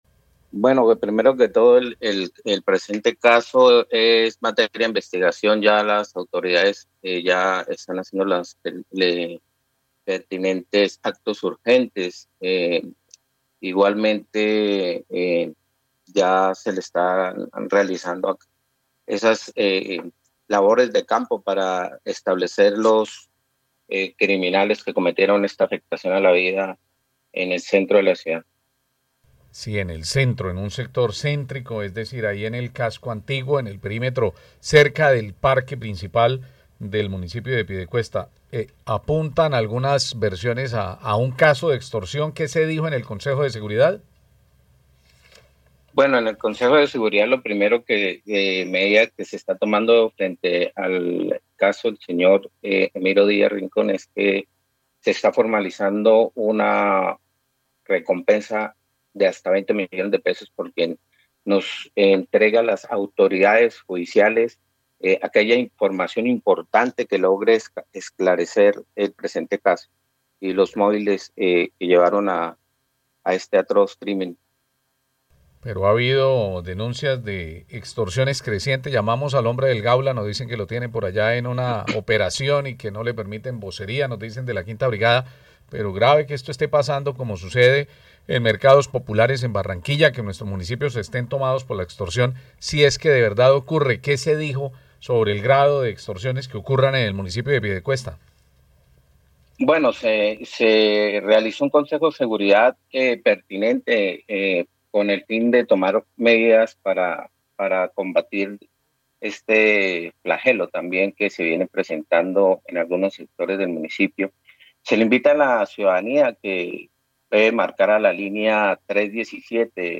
Fernando García, secretario de Seguridad de Piedecuesta habla de recientes crímenes en su municipio